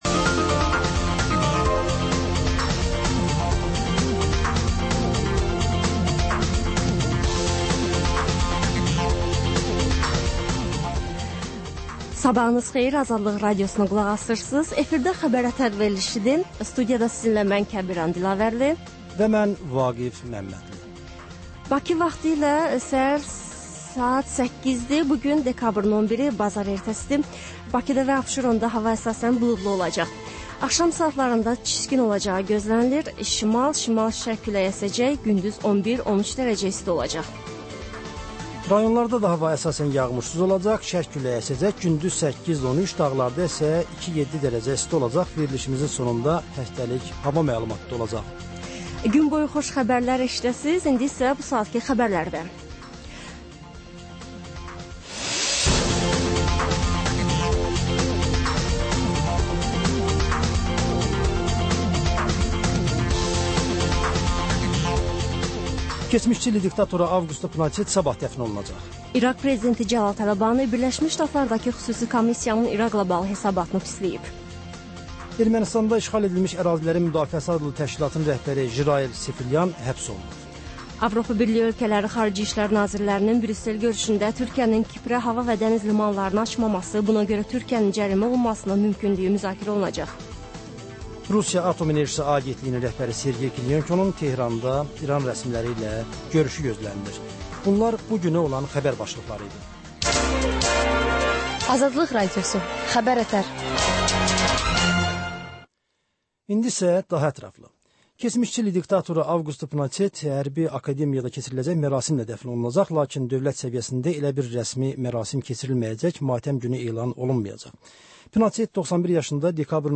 Səhər-səhər, Xəbər-ətər: xəbərlər, reportajlar, müsahibələr İZ: Mədəniyyət proqramı. Və: Tanınmışlar: Ölkənin tanınmış simalarıyla söhbət.